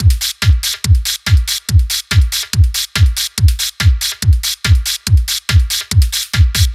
NRG 4 On The Floor 032.wav